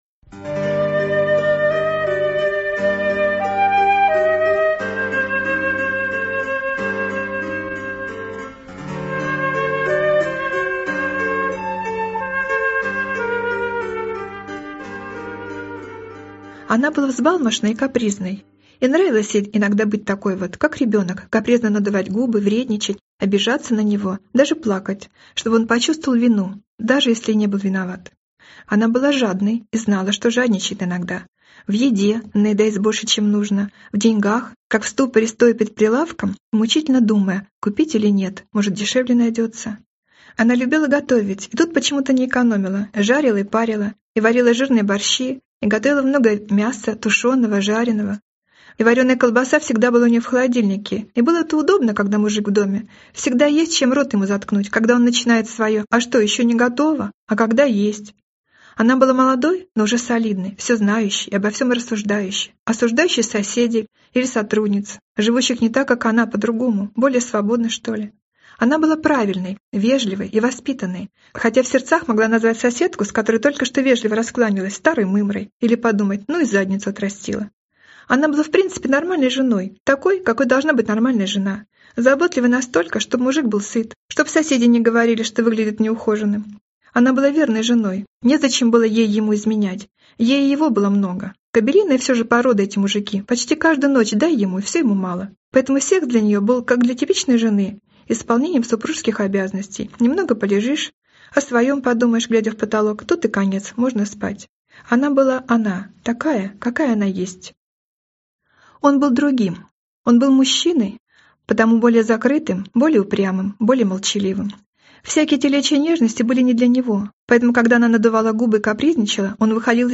Аудиокнига Жизнь по Плану | Библиотека аудиокниг